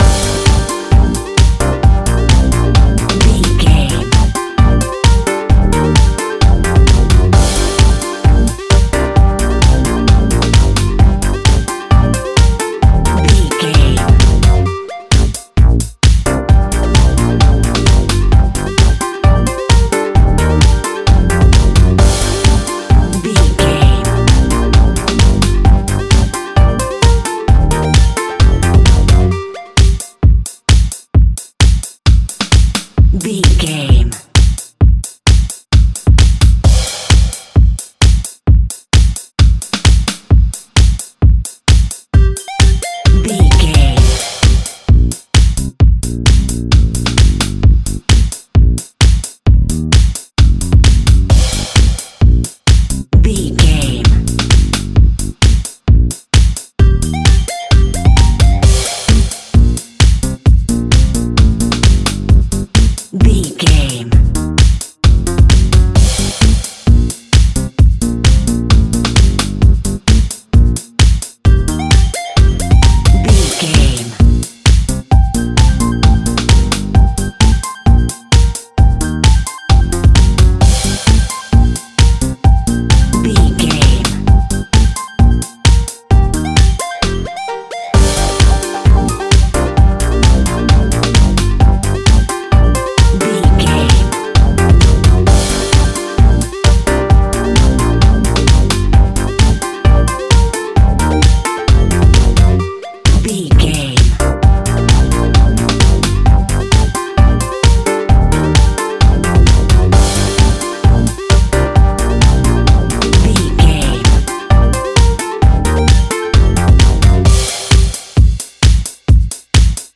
Funk House Theme.
Aeolian/Minor
driving
energetic
uplifting
lively
drum machine
synthesiser
post disco
electro house
synth pop
funky house
joyful